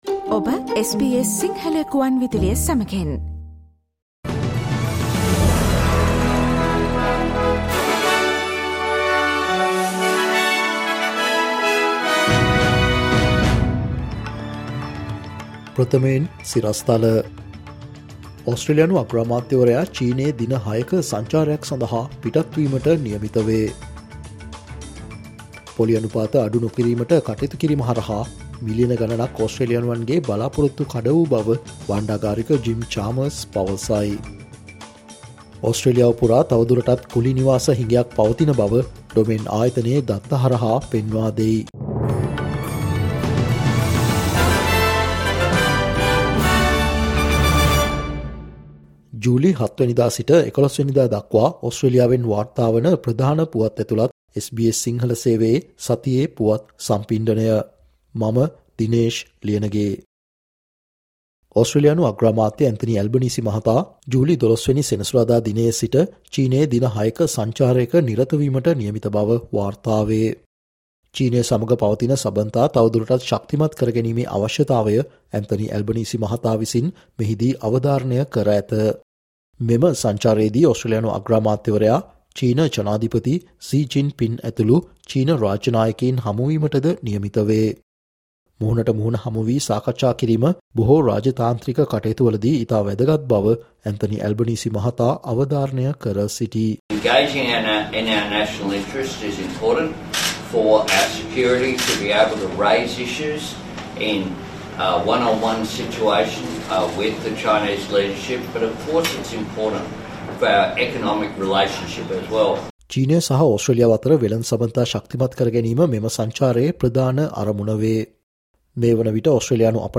ජූලි 07 වනදා සිට ජූලි 11 වනදා දක්වා වන මේ සතියේ ඕස්ට්‍රේලියාවෙන් වාර්තාවන පුවත් ඇතුලත් සතියේ පුවත් ප්‍රකාශයට සවන් දෙන්න